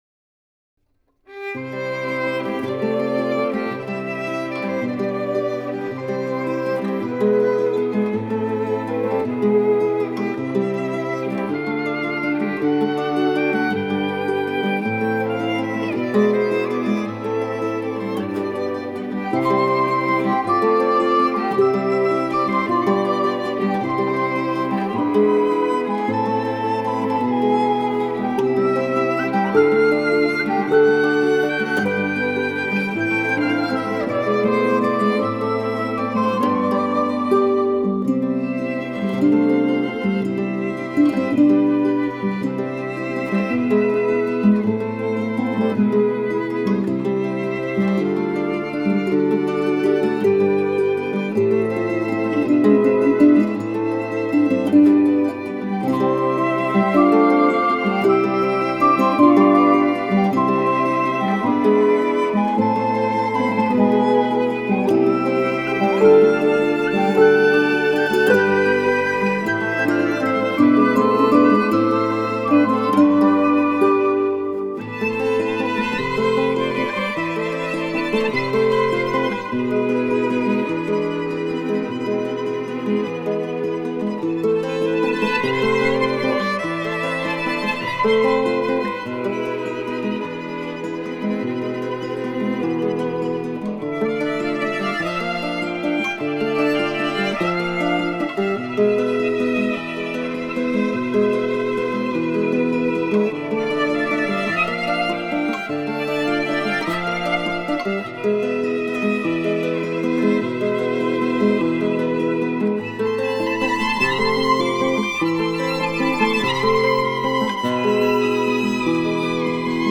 Акустическая запись скрипки,гитары и блокфлейты
Скрипка Гитара Блокфлейта Инструментальная музыка